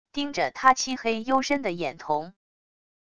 盯着他漆黑幽深的眼瞳wav音频生成系统WAV Audio Player